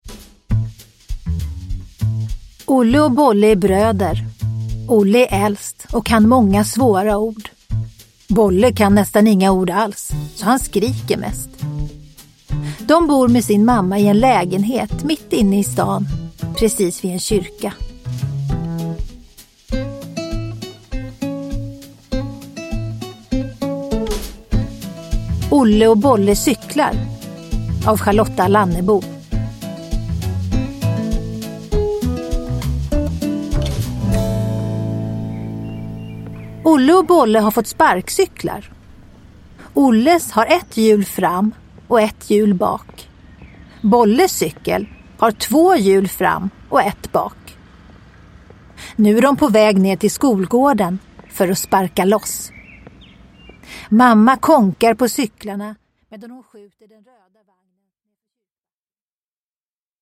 Uppläsare: Tova Magnusson